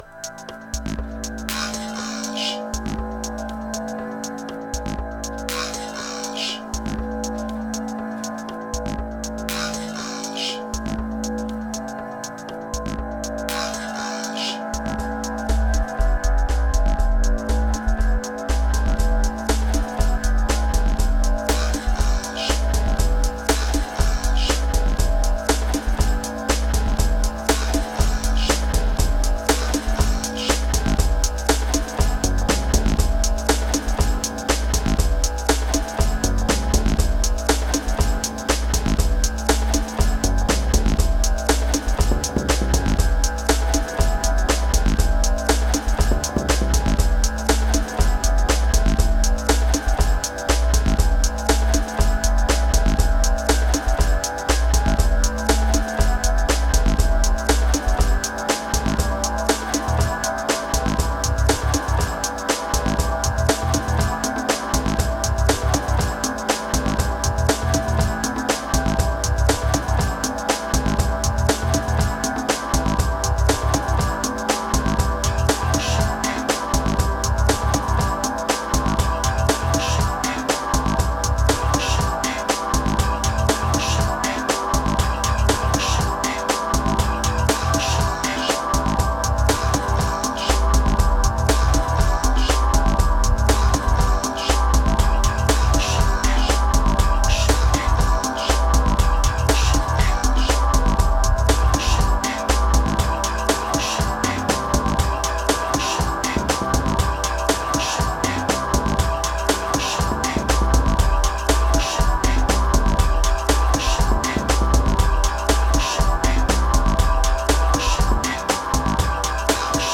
Triphop Electric Beat Energy Dark Quotes